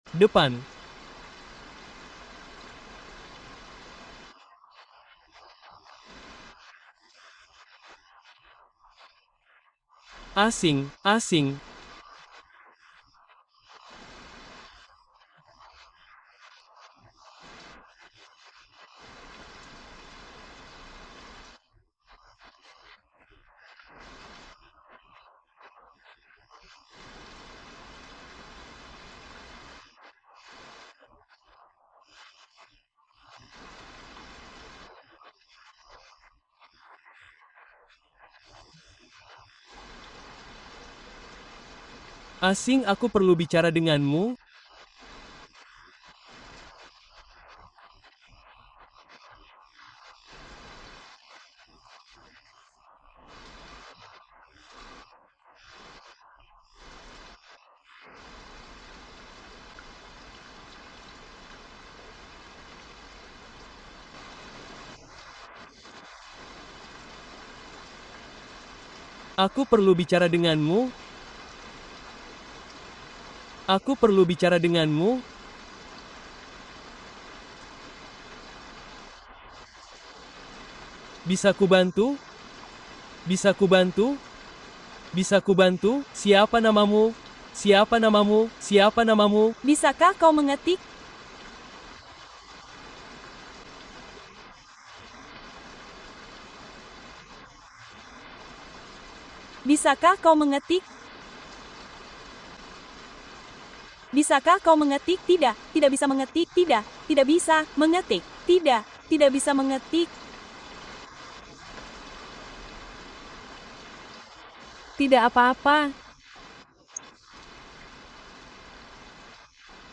Relájate mientras entrenas tu oído: inglés bajo la lluvia (parte II)